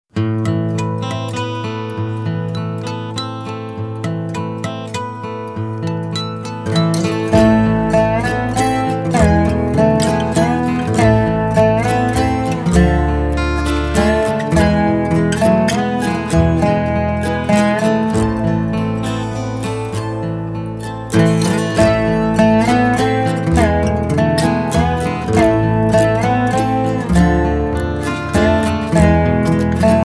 An album of Swedish Bluegrass music.